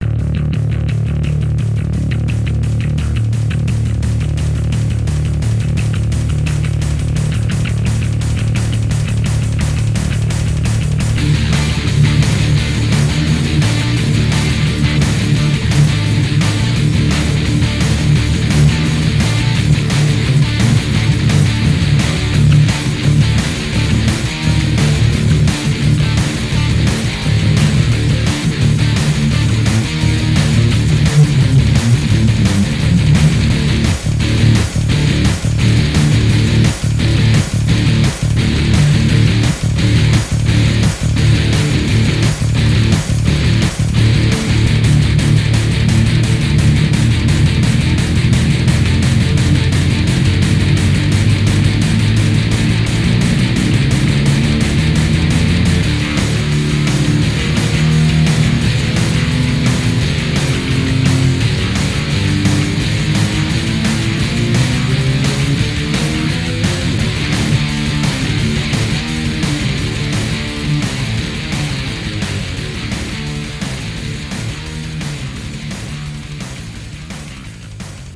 Fight Theme (Metal)